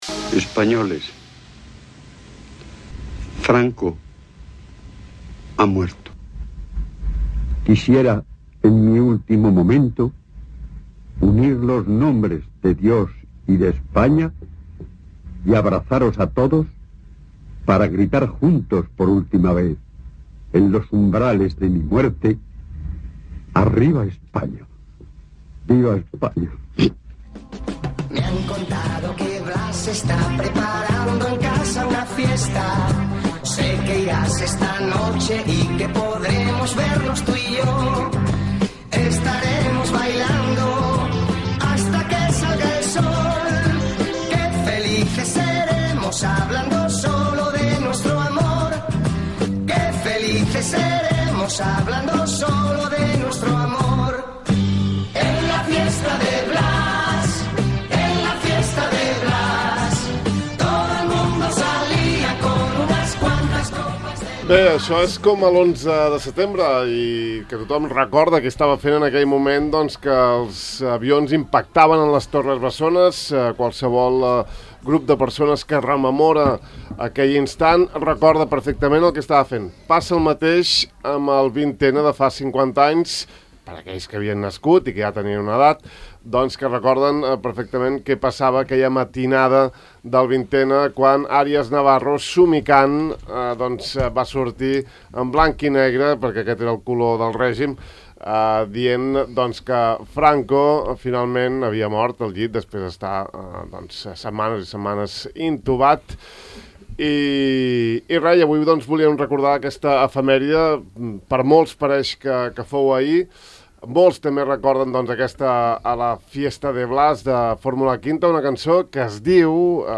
Avui, 20-N, mig segle ja de la mort del dictador Francisco Franco, hem organitzat una taula rodona al De far a far per recordar com es va viure aquest moment històric a la nostra illa.